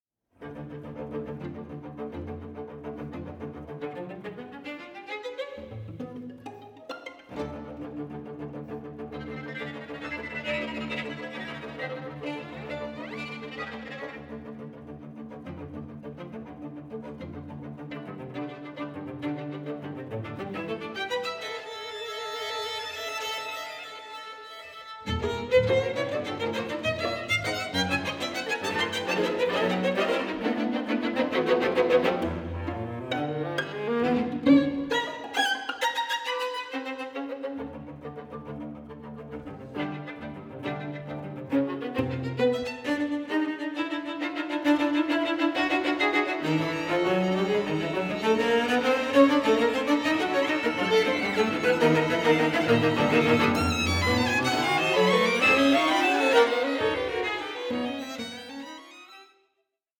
Vivacissimo 3:42